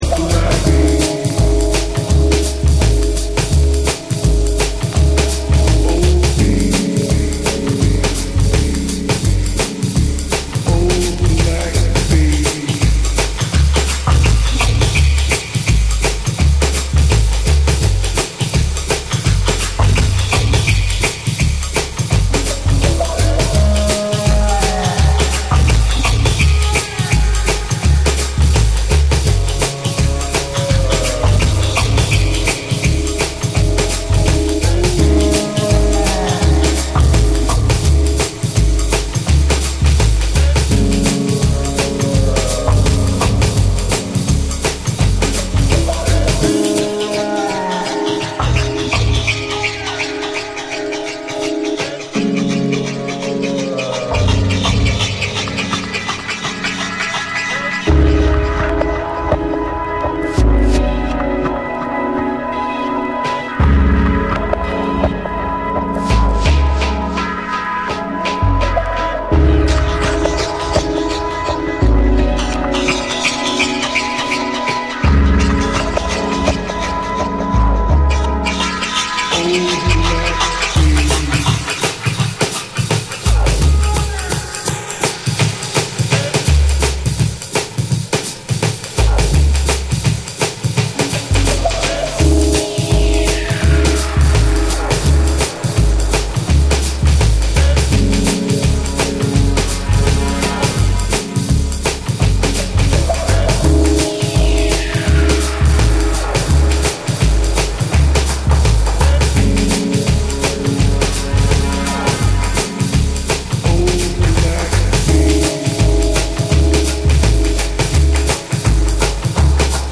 BREAKBEATS / JUNGLE & DRUM'N BASS